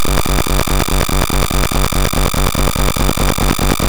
Laser_01.mp3